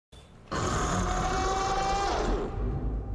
Play Bazelgeuse Roar - SoundBoardGuy
Play, download and share bazelgeuse roar original sound button!!!!
bazelgeuse-roar.mp3